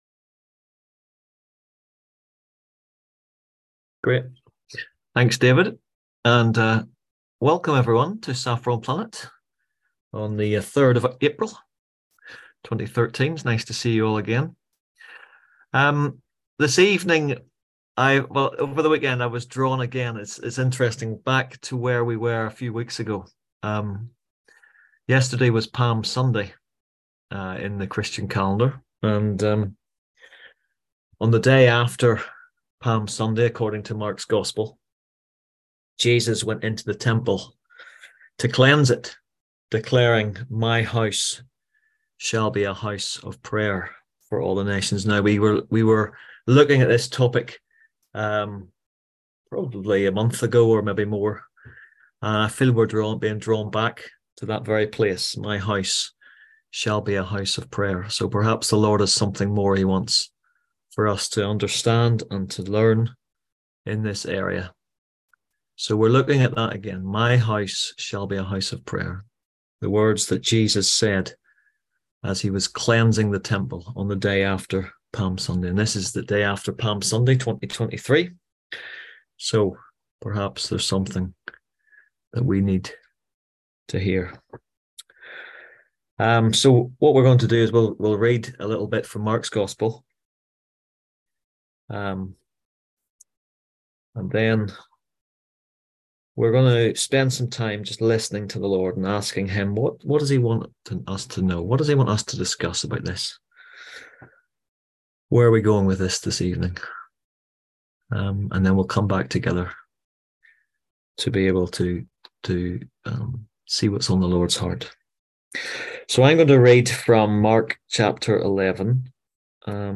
On April 3rd at 7pm – 8:30pm on ZOOM